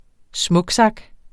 Udtale [ ˈsmɔgˌsɑg ]